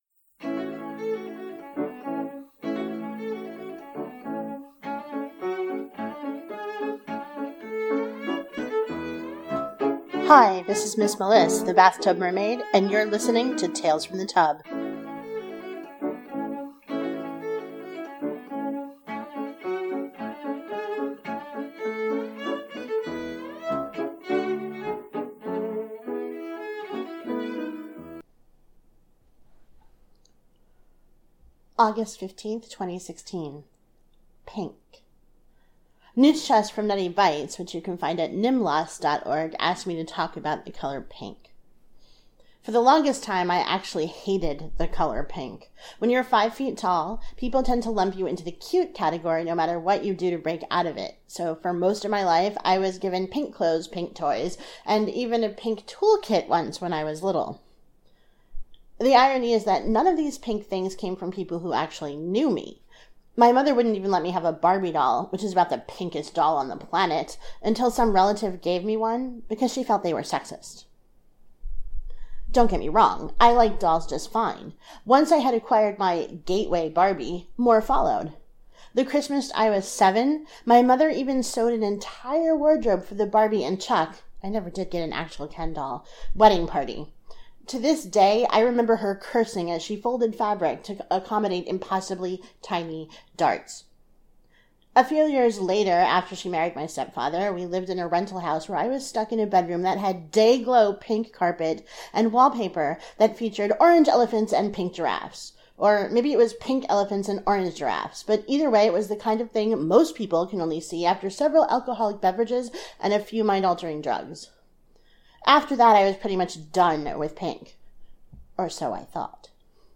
• Music used for the opening and closing is David Popper’s “Village Song” as performed by Cello Journey.